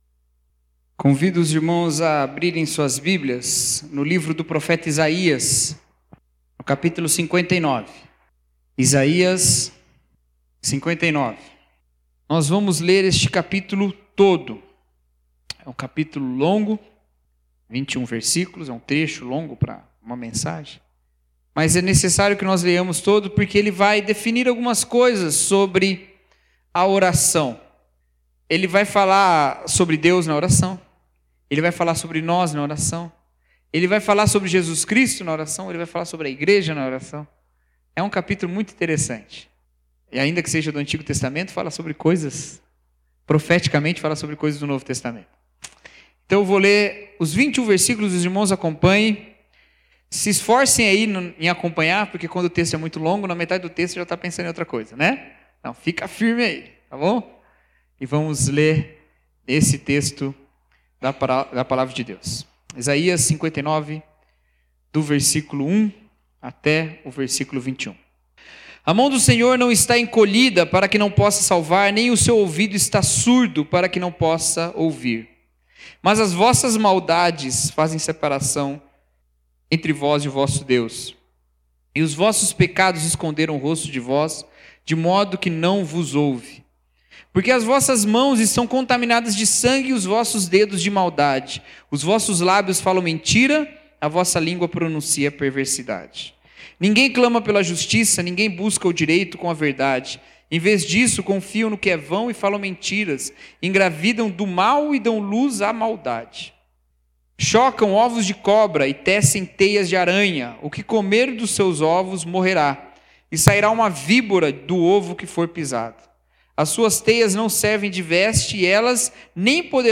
Mensagem: Oração: Relacionamento com Deus